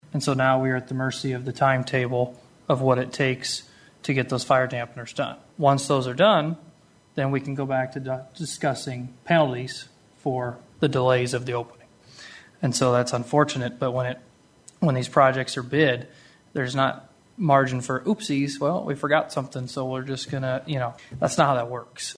A PROGRESS REPORT AND DISCUSSION ON THE CONSTRUCTION OF THE NEW LOCAL LAW ENFORCEMENT CENTER WAS GIVEN TUESDAY AT THE WOODBURY COUNTY SUPERVISOR’S MEETING.
NELSON REMINDED THE BOARD THAT THE 38 FIRE DAMPANERS THAT ARE REQUIRED BY STATE CODE WERE NEVER INCLUDED IN THE PROJECT BIDDING, AND THAT IS THE REASON BEHIND THE DELAYS: